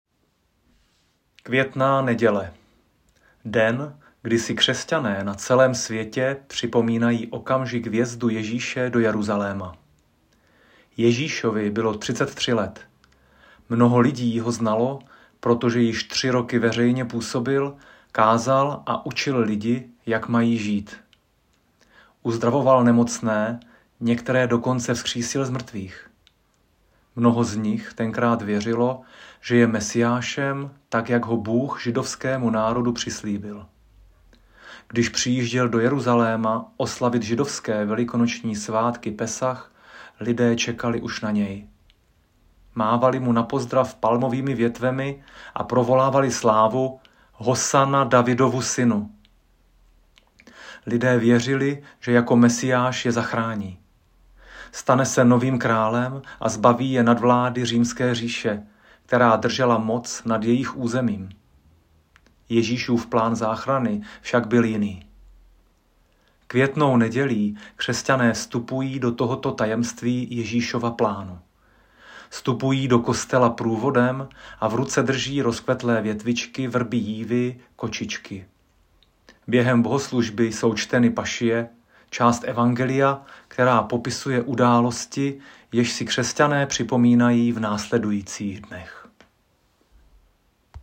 Lektorský úvod ke Květné neděli